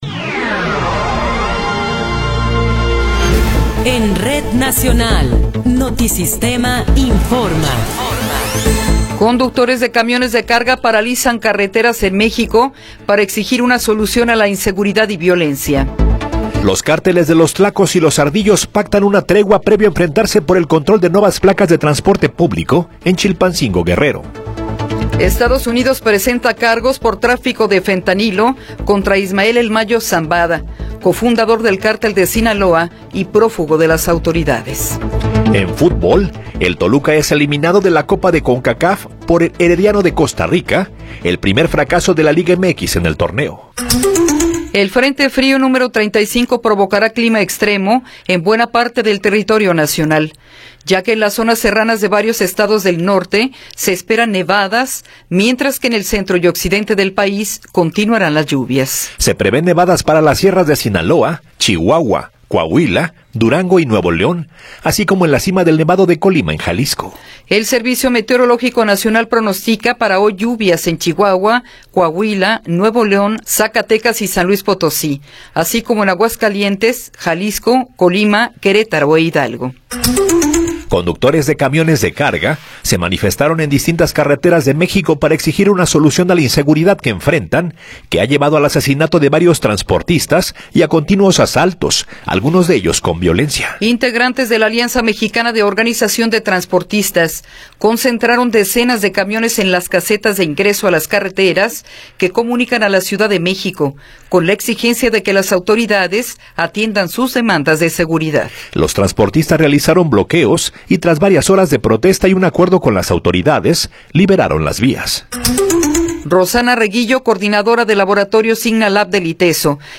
Noticiero 8 hrs. – 16 de Febrero de 2024
Resumen informativo Notisistema, la mejor y más completa información cada hora en la hora.